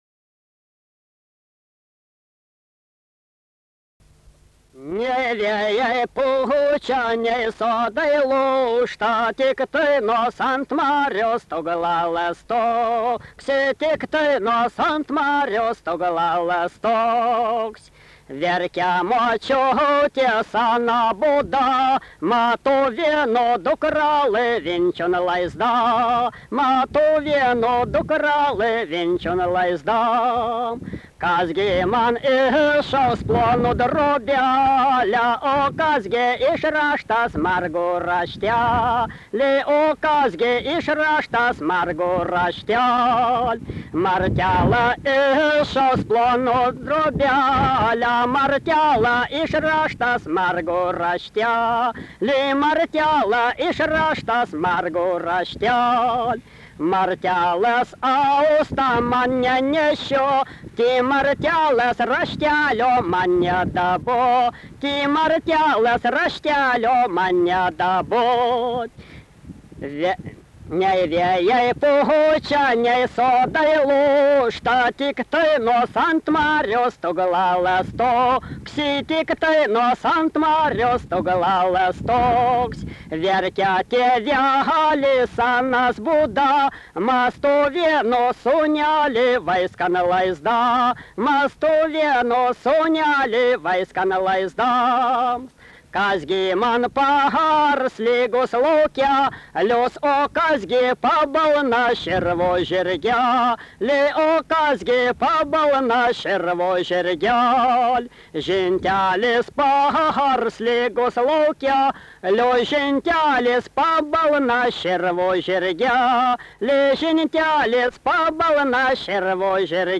daina, kalendorinių apeigų ir darbo